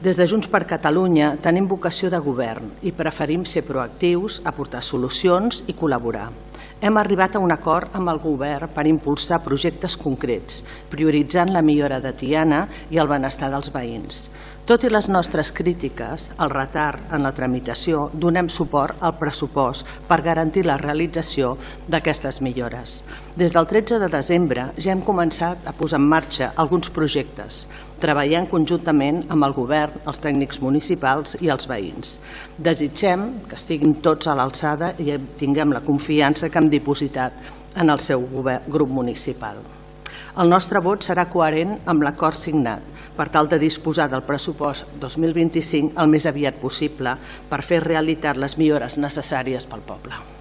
Pel que fa a la portaveu de Junts per Catalunya Tiana, Montse Torres, ha votat a favor de la proposta d’acord en coherència amb el pacte de pressupostos que van signar amb Junts per Tiana: